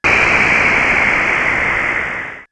GasReleasing08.wav